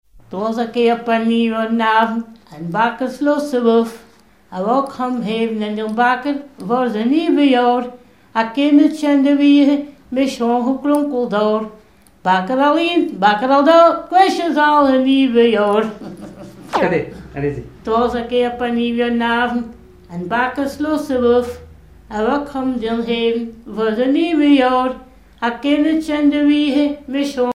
Chant en flamand
Genre strophique
Mission dans les Flandres
Pièce musicale inédite